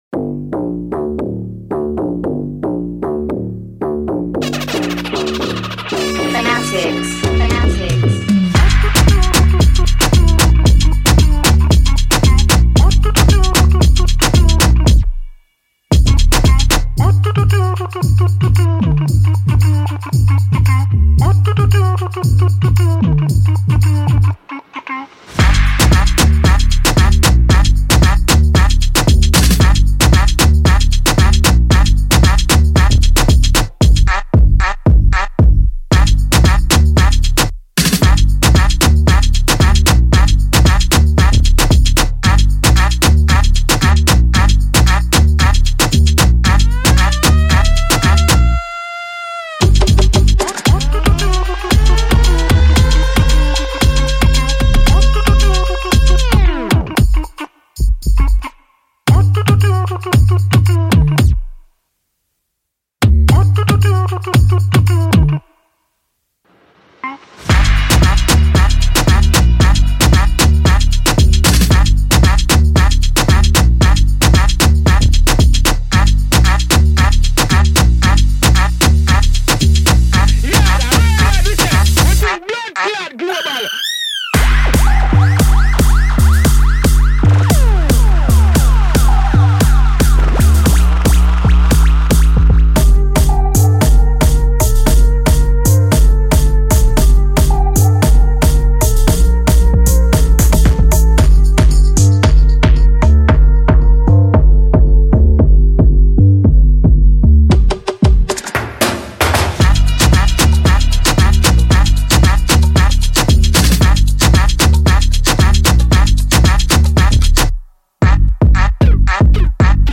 2024 in Dancehall/Afrobeats Instrumentals